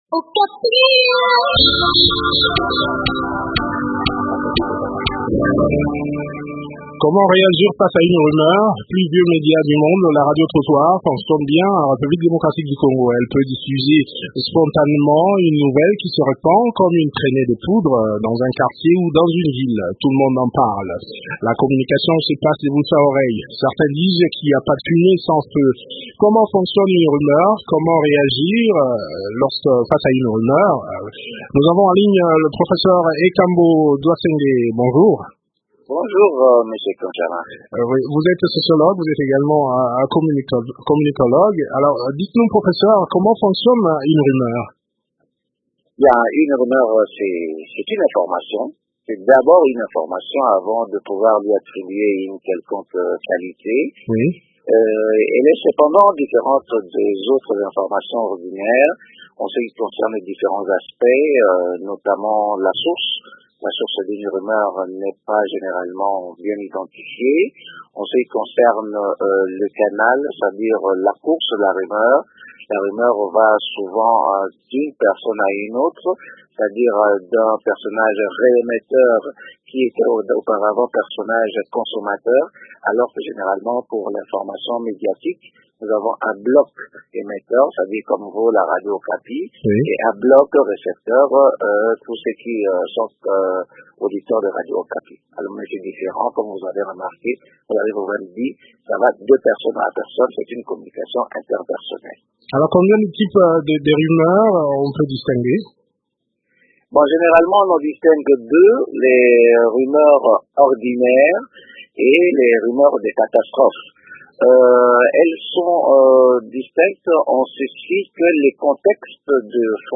sociologue et communicologue